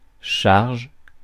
Ääntäminen
IPA: [ʃaʁʒ]